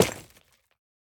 Minecraft Version Minecraft Version 1.21.5 Latest Release | Latest Snapshot 1.21.5 / assets / minecraft / sounds / block / pointed_dripstone / land3.ogg Compare With Compare With Latest Release | Latest Snapshot
land3.ogg